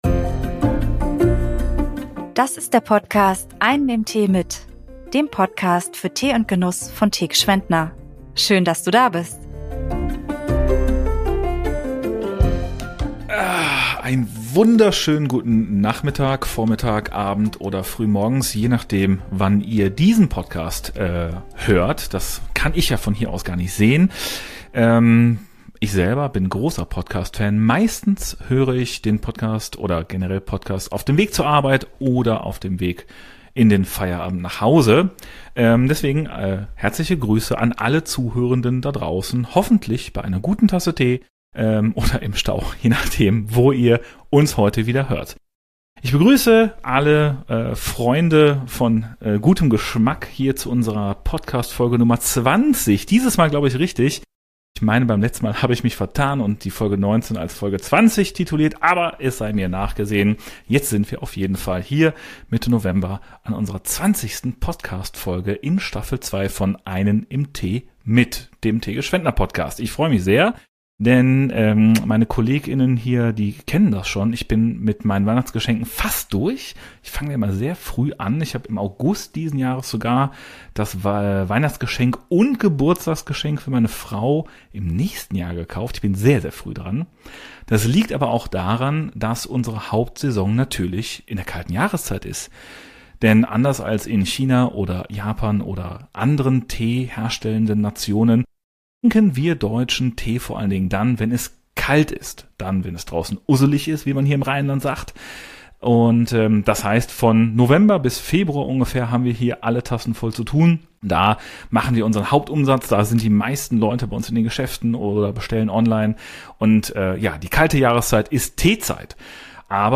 #20 - Umami in Tee & Küche – ein Gespräch mit SoulSpice ~ Einen im Tee mit...